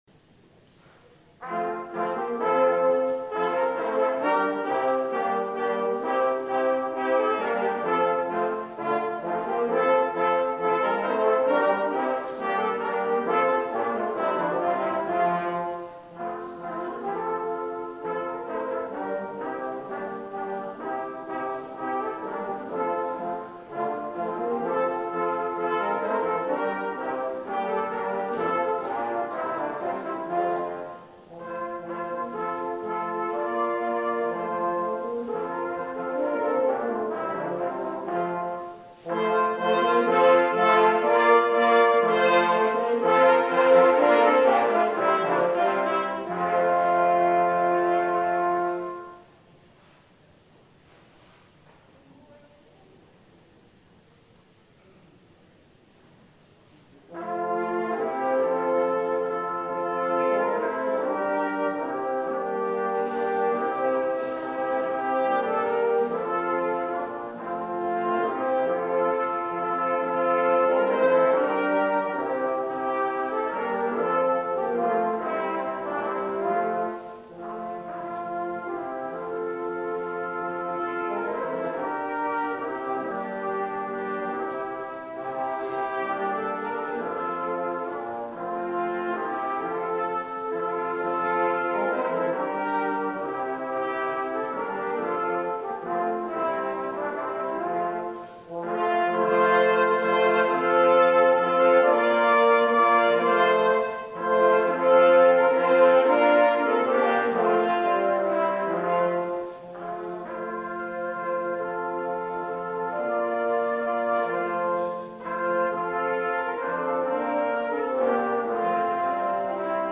Various Brass Preludes on Easter Sunday 2013